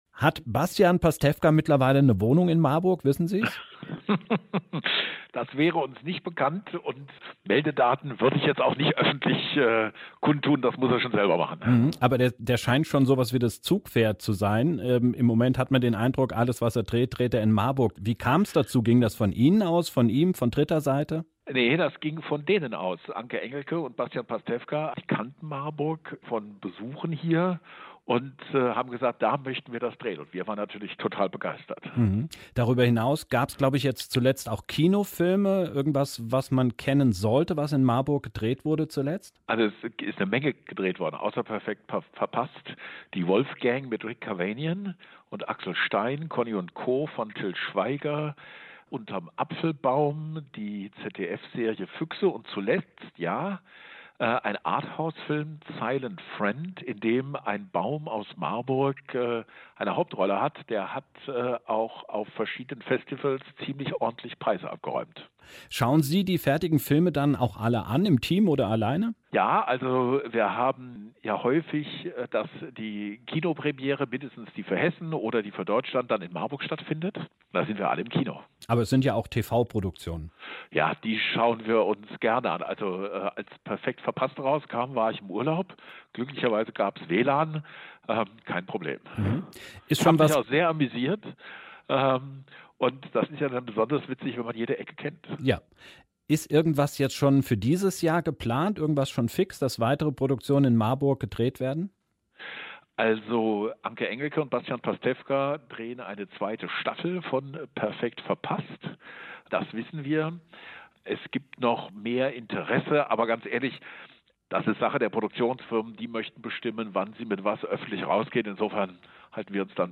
Drum hat er einfach mal kurz zum Telefonhörer gegriffen und Marburgs Oberbürgermeister Thomas Spies angerufen...